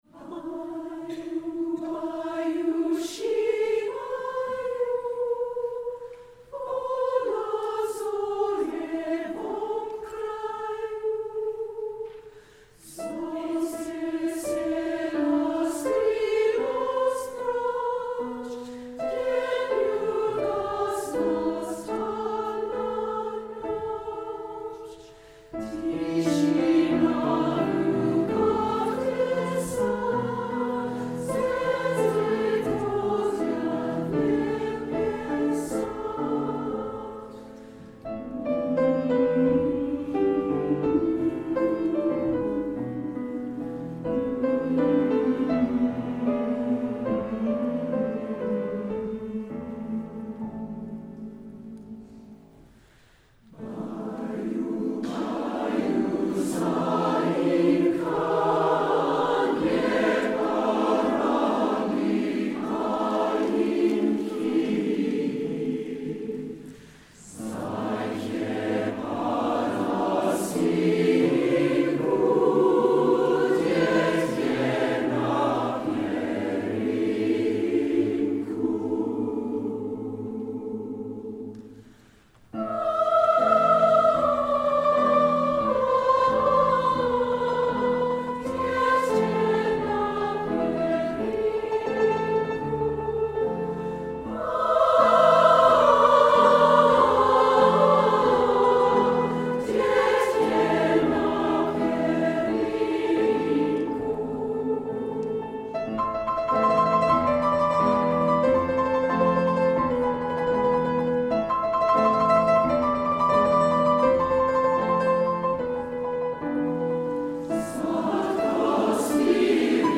Choral Multicultural
Scored for mixed choir with four-hand piano
Russian Folk Song
SATB